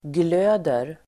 Uttal: [gl'ö:der]